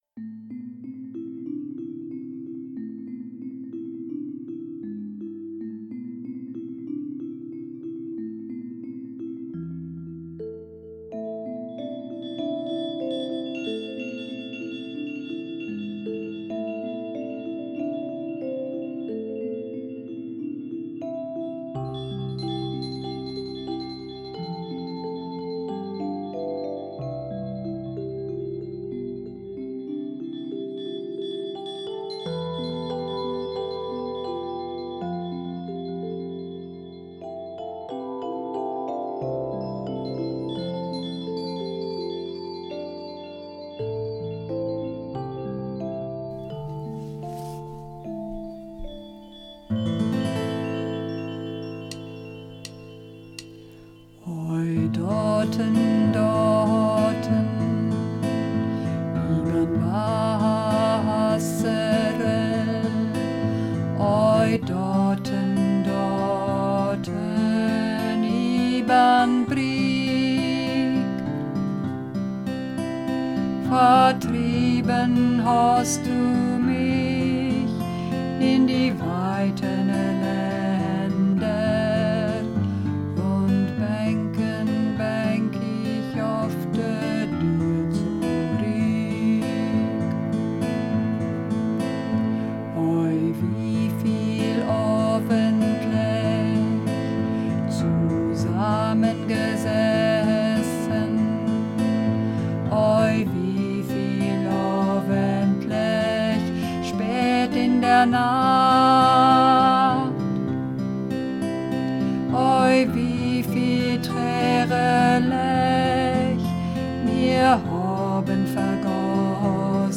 Oj, dortn, dortn (Bass - mit Musik)
Oj_dortn_dortn__2_Bass.mp3